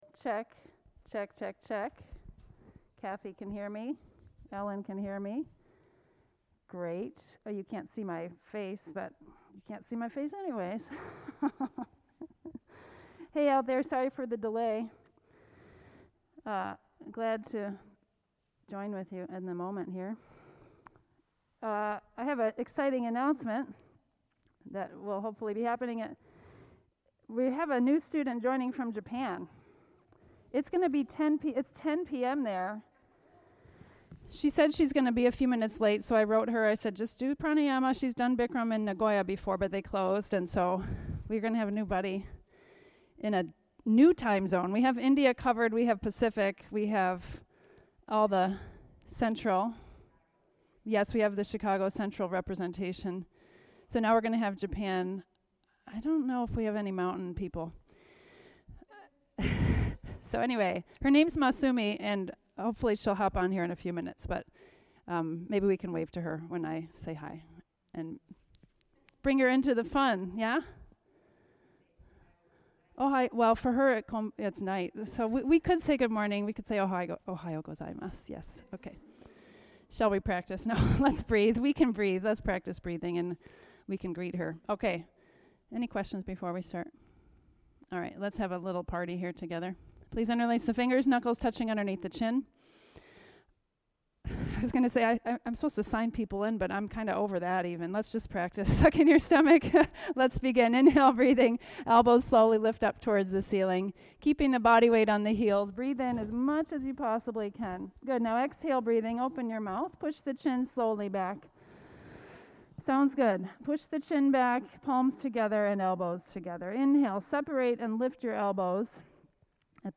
Bikram Yoga 90-minute Class